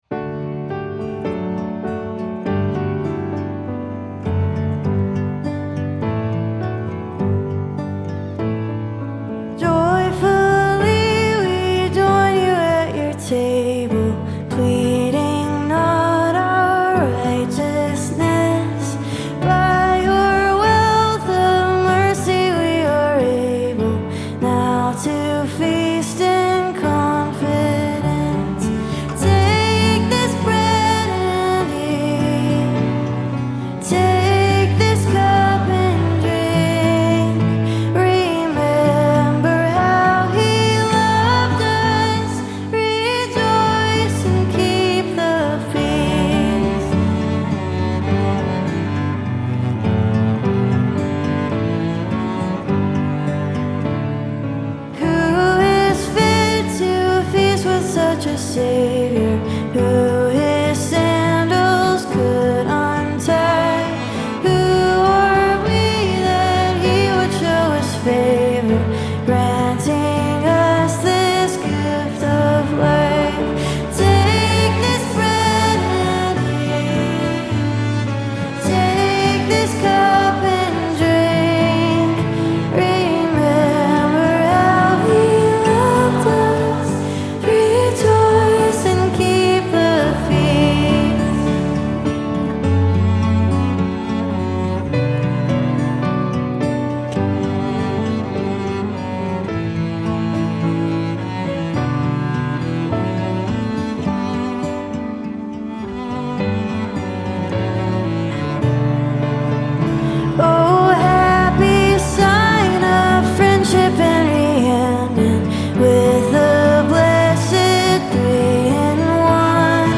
Jesus Worship